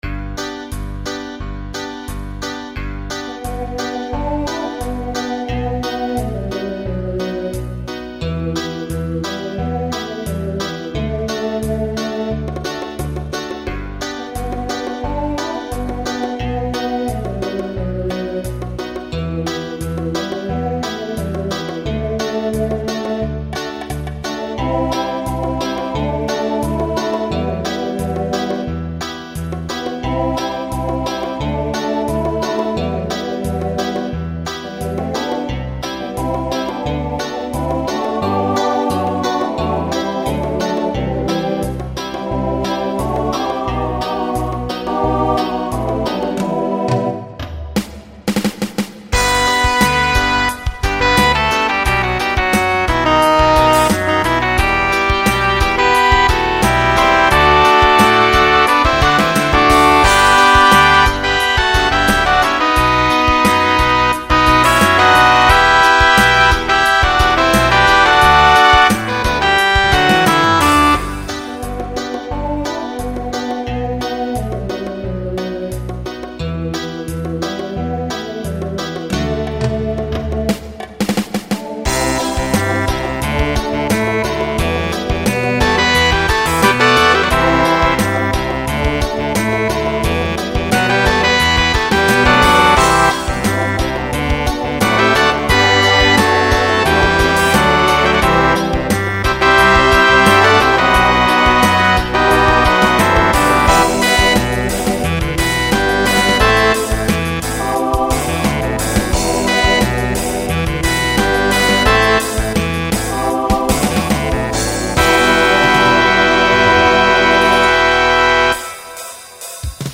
TTB/SSA